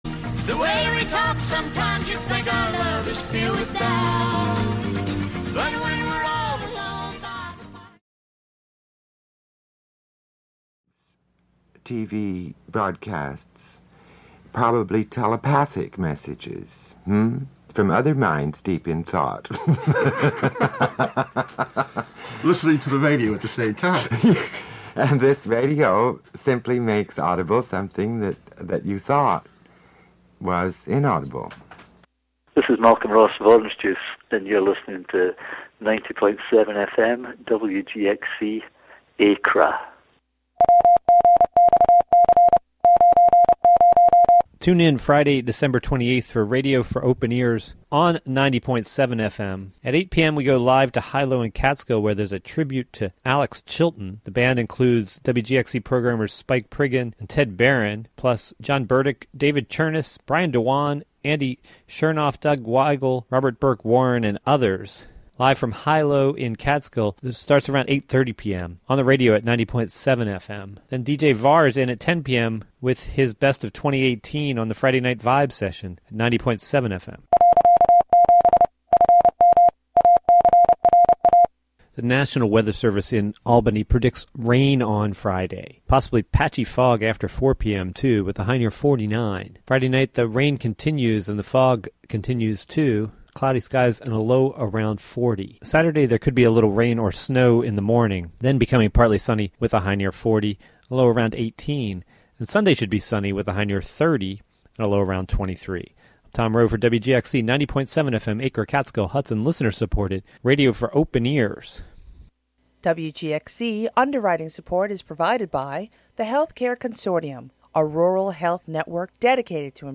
Broadcast live from the Hudson studio.